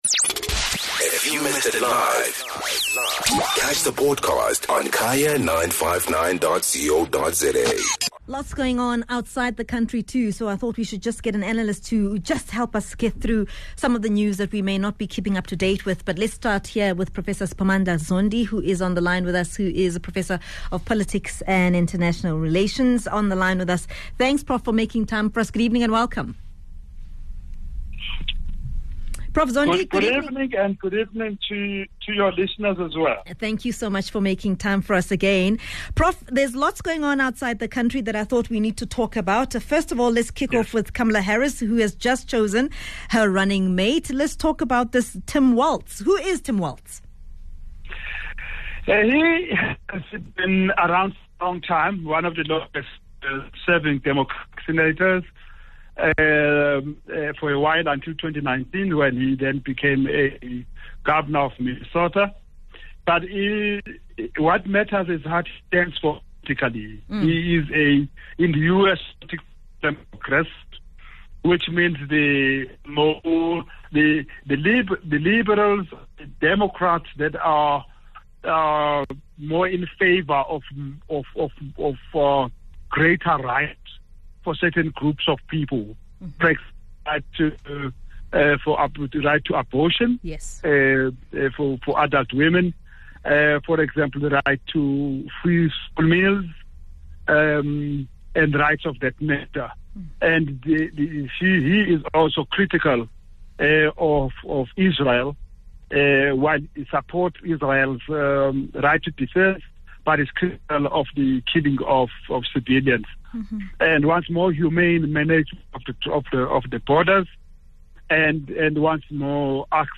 7 Aug Analysis: International headlines